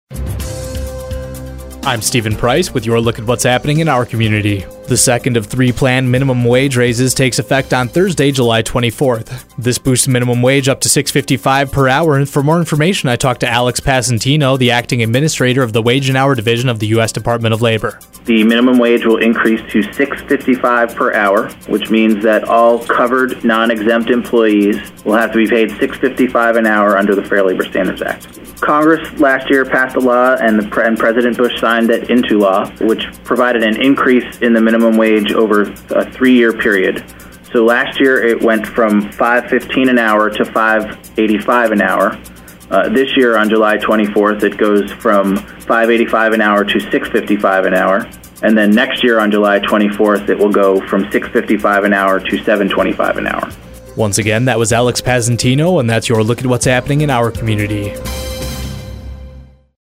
It will raise to $6.55 per hour for employees who aren't currently exempt. Alex Passantino, acting administrator of the wage and hour division of the US Department of Labor, comments.